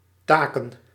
Ääntäminen
IPA: /ˈtaːkə(n)/